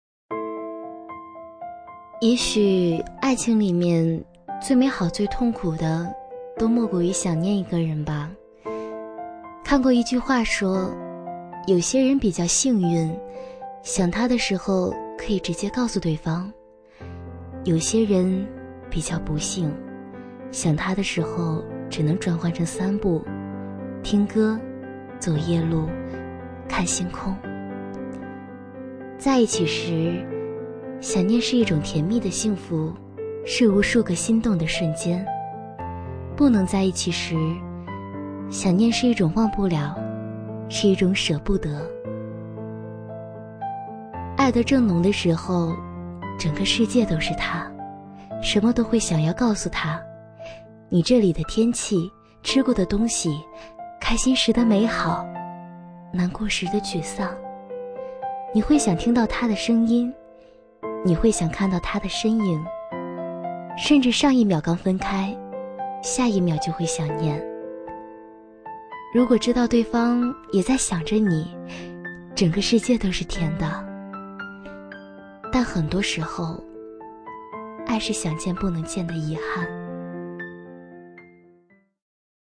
B类女13
【女13号情感夜听】温柔磁性